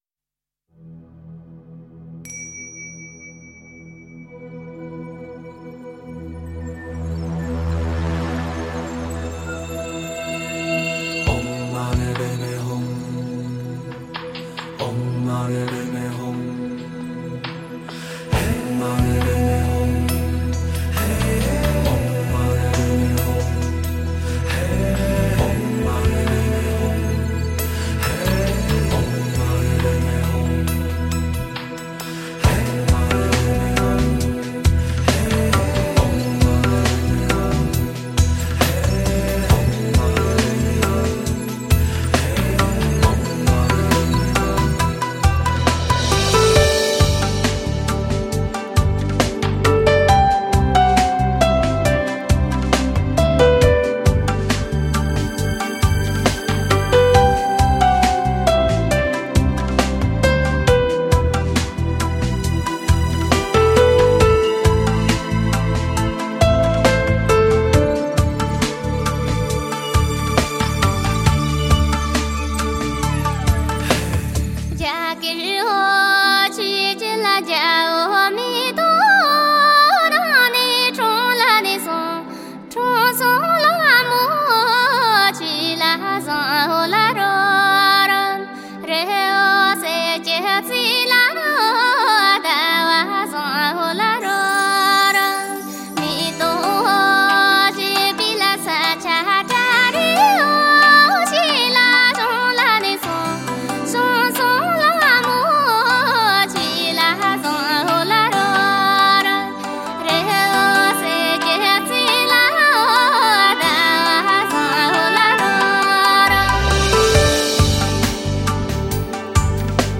跨界欢喜新音乐元素；来自三大藏区的原生态民歌，融入印度尼泊尔交响乐色彩。